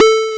SYN MELOSYN.wav